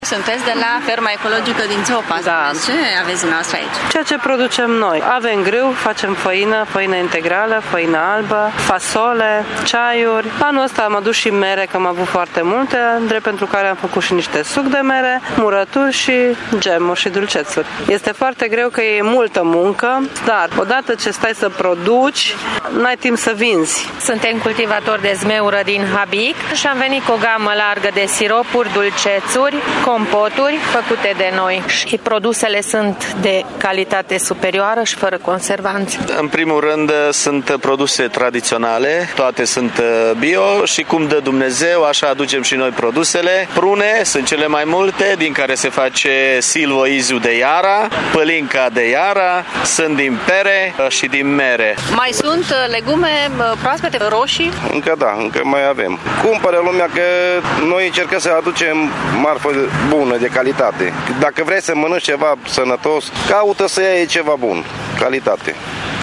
Producătorii mureșeni recunosc că depun foarte multe eforturi pentru ca mărfurile lor să fie de calitate și doar cunoscătorii reușesc să le aprecieze la adevărata lor valoare: